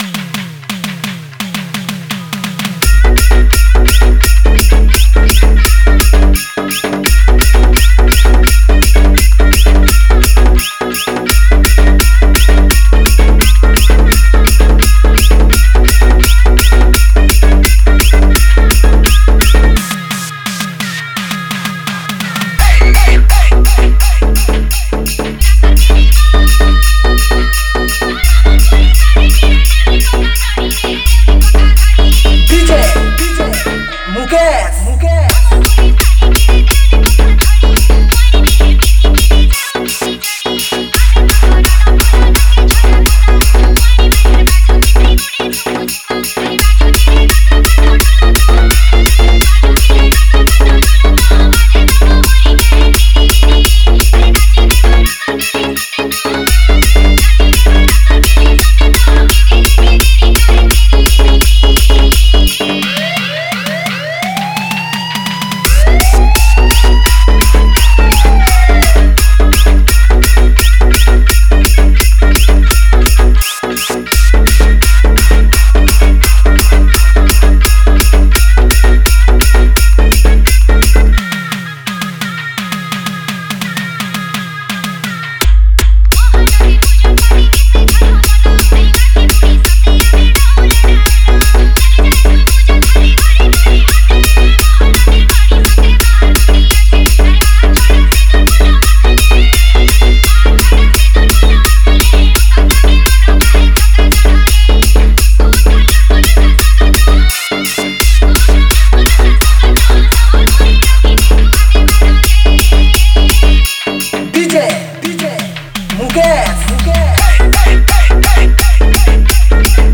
Category: 2021 Holi Special Sambalpuri DJ Remix Songs